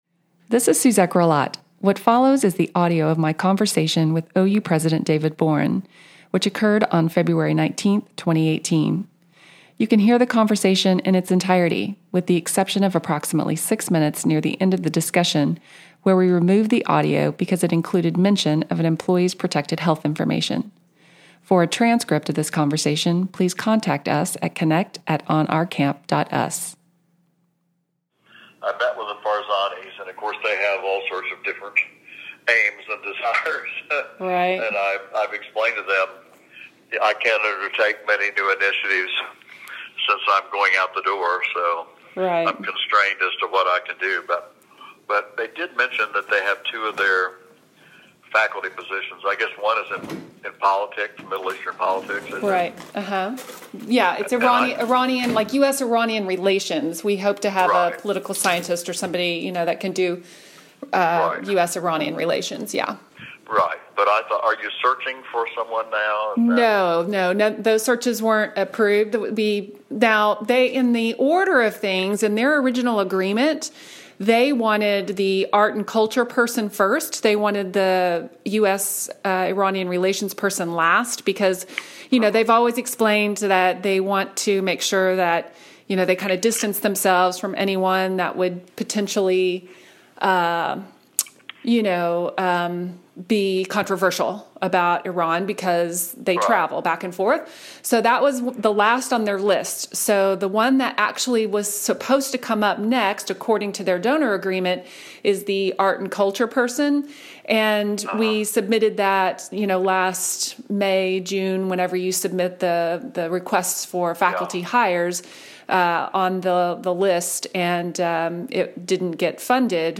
David-Boren-Conversation-Audio.mp3